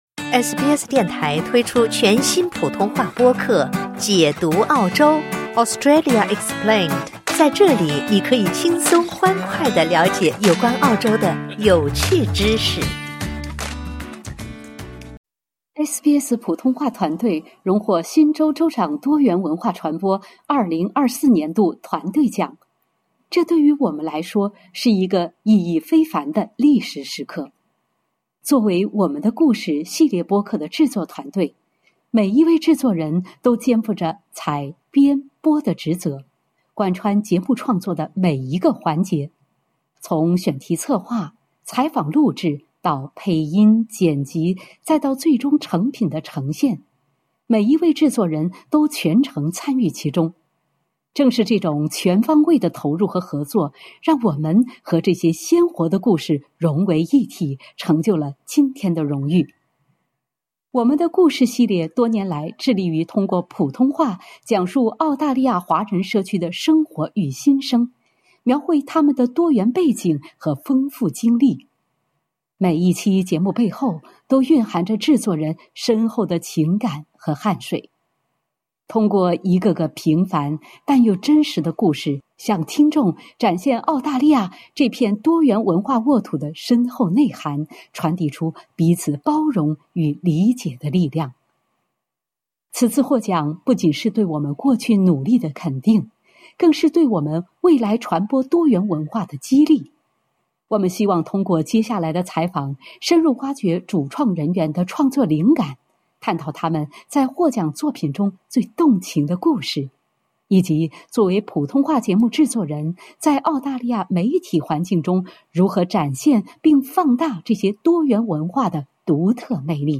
本期采访，将邀请主创人员分享他们的创作灵感，探讨他们在获奖作品中最动情的故事，以及作为普通话节目制作人，在澳大利亚媒体环境中如何展现并放大这些多元文化的独特魅力。